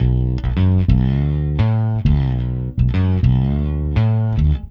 Ala Brzl 1 Bass-B.wav